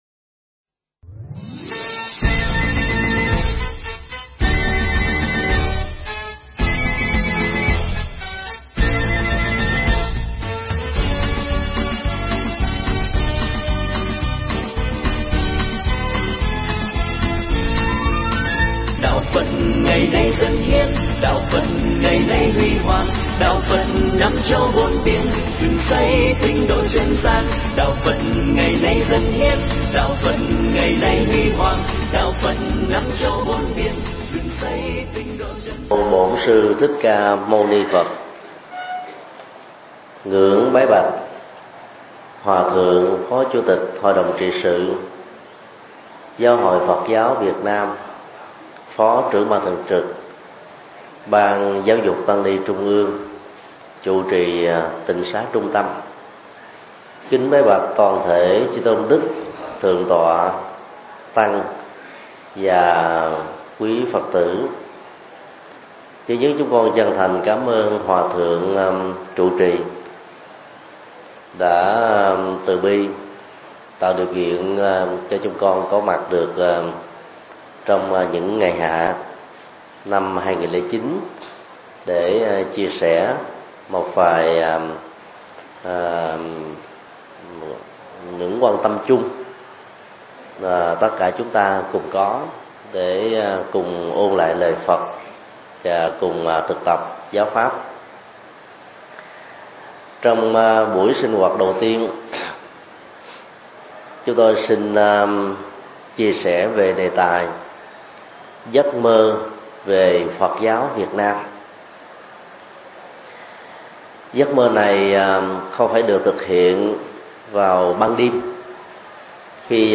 Pháp thoại Giấc Mơ Về Phật Giáo Việt Nam
giảng tại Trường hạ Tịnh Xá Trung Tâm